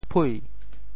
How to say the words 啡 in Teochew？
phui2.mp3